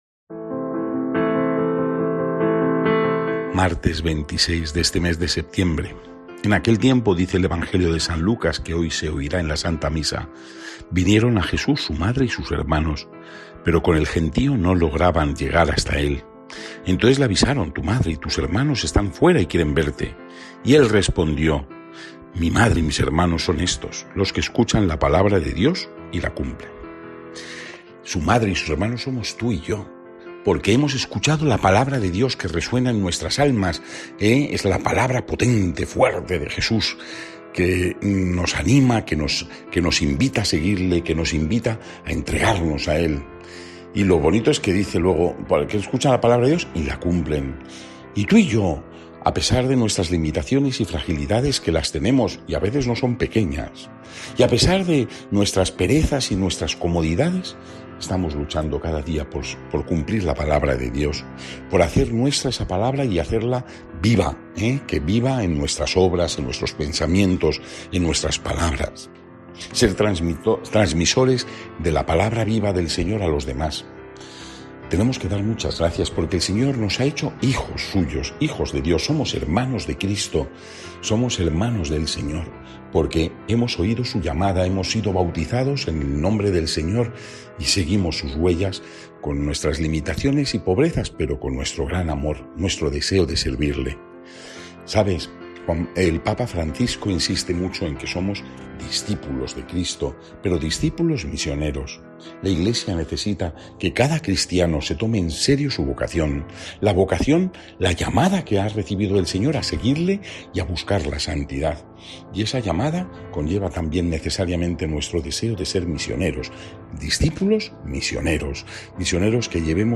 Lectura del santo evangelio según san Lucas 8, 19-21